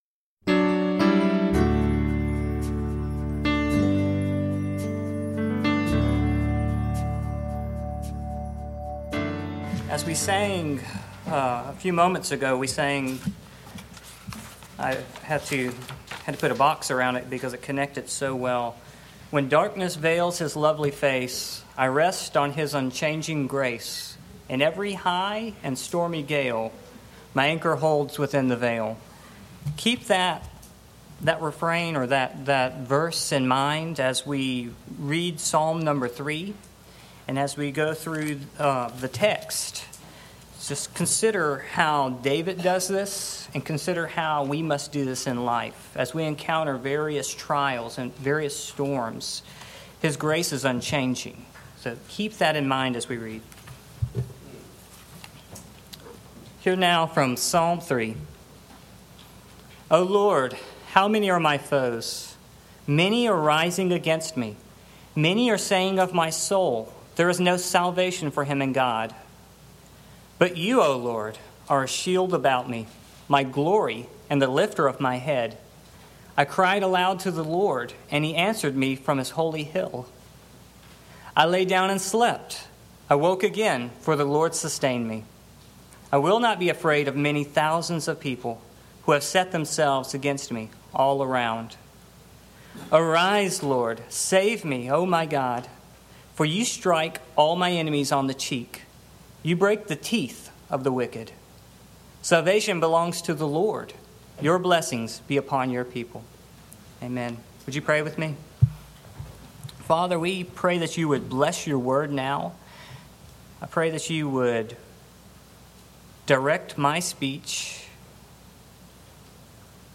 Psalm 3 Service Type: Morning Main Point